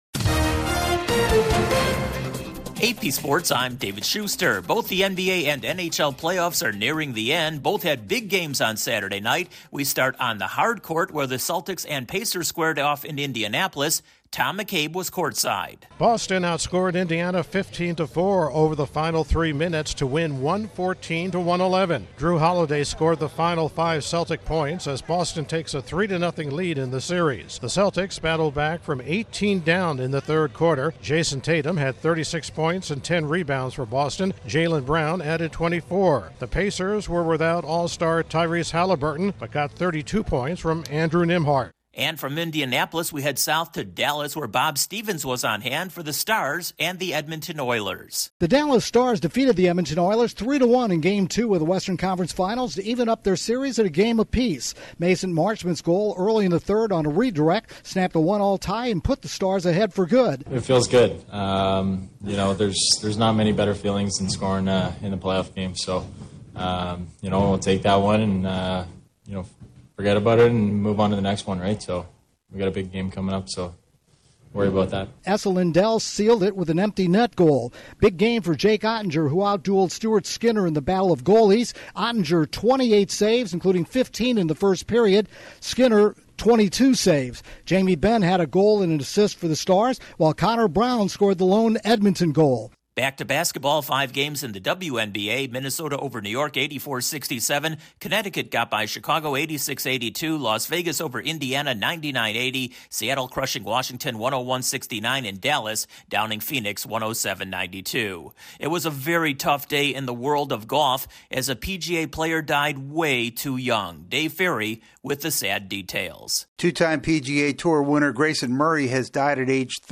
The Celtics are one win away from the NBA Finals, the Stars even up their series on the ice, a full slate of MLB action and the world of golf is in mourning following the death of a young PGA player. Correspondent